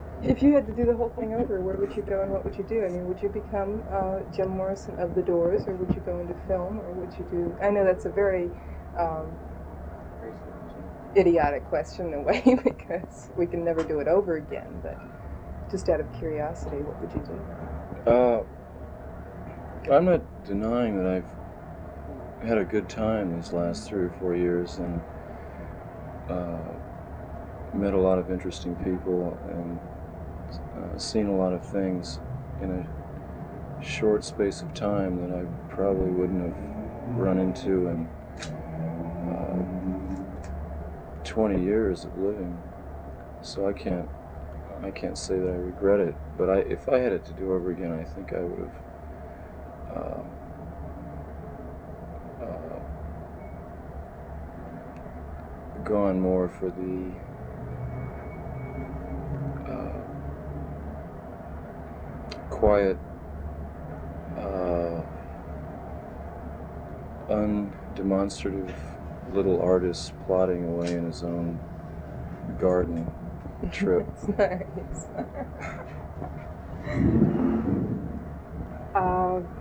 17 If You Had to Do the Whole Thing over What Would You Have Done (The Lost Interview Tapes - Volume Two).flac